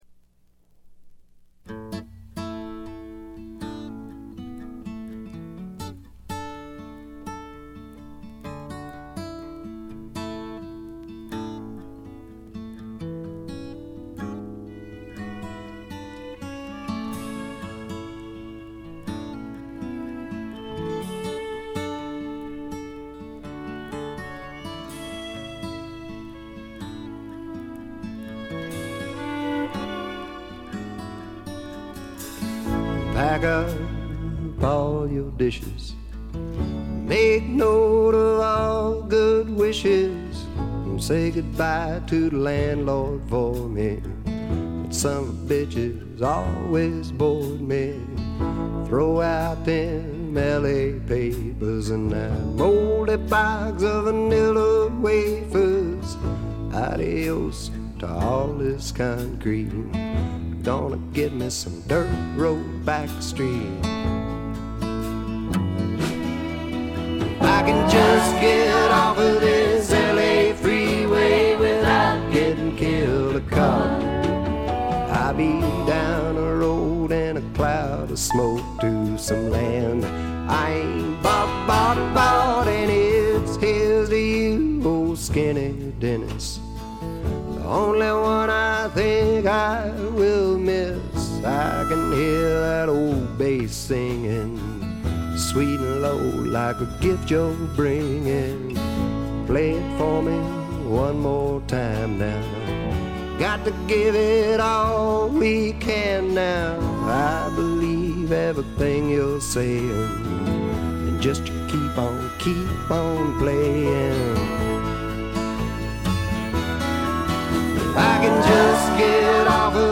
ほとんどノイズ感無し。
朴訥な歌い方なのに声に物凄い深さがある感じ。
試聴曲は現品からの取り込み音源です。
Guitar, Vocals